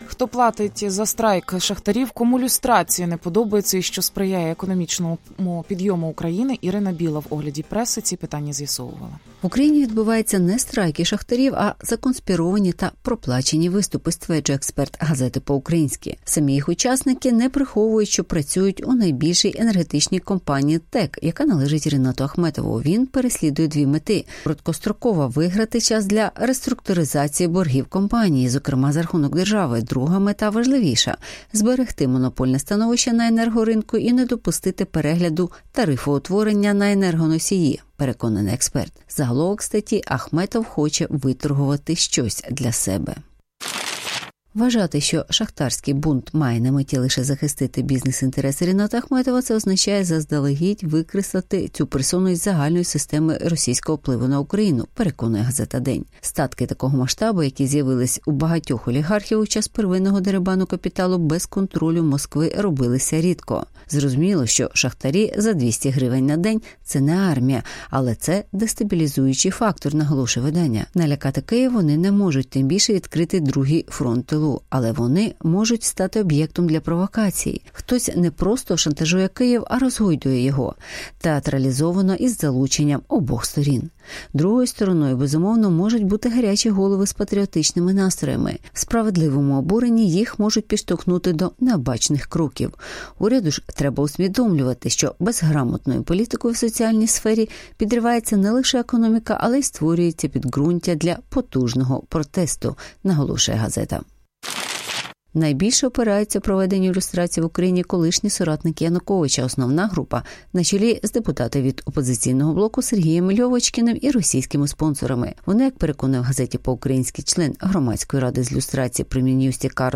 Ахметов розхитує Київ (огляд преси)